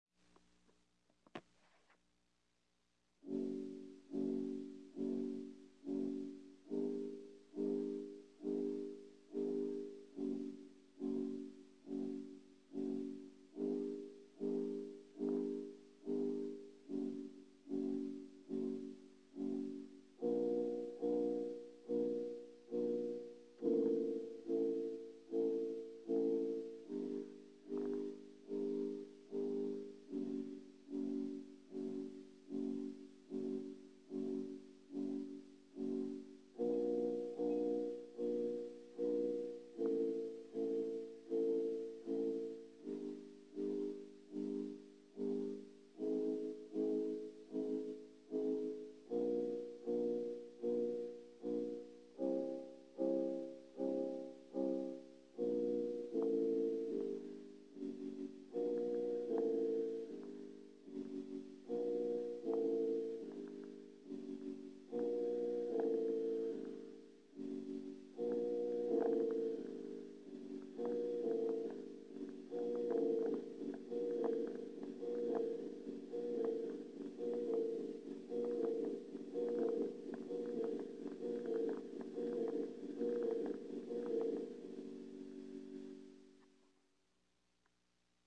描述：A dark chime rings in appearing an invasion e.g. by zombies, some breath can be heard from malefic creature.
标签： spooky fear terror darkness breath anxious bell invasion evil chime zombie thrill creepy sinister horror monster scary
声道立体声